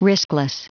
Prononciation du mot riskless en anglais (fichier audio)
Prononciation du mot : riskless